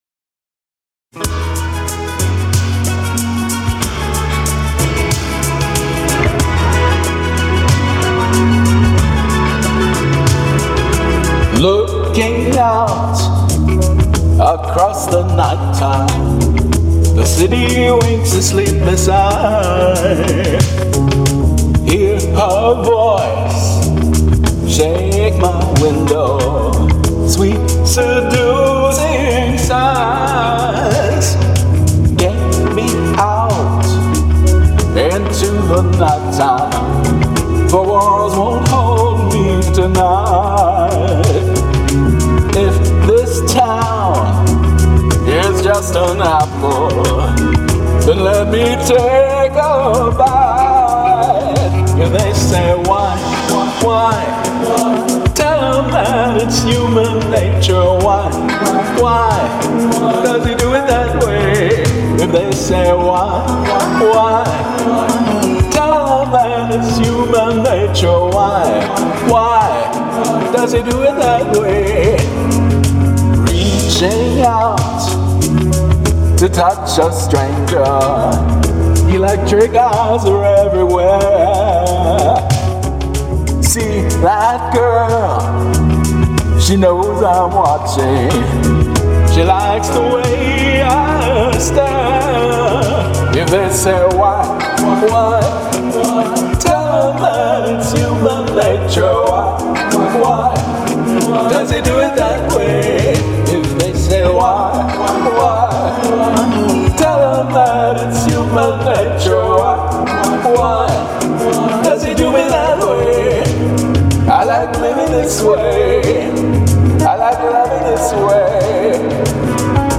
My version here brings a sensual quality to the singing.